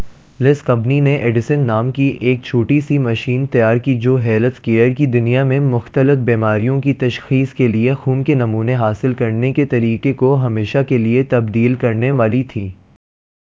deepfake_detection_dataset_urdu / Spoofed_TTS /Speaker_14 /128.wav